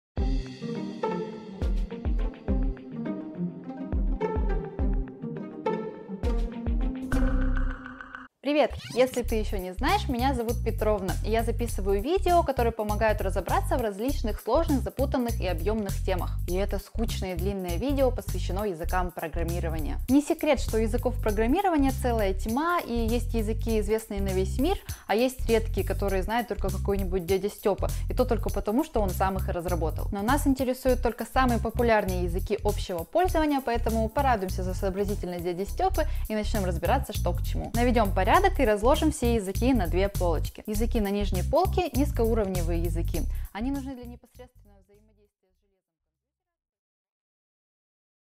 Аудиокнига Языки программирования | Библиотека аудиокниг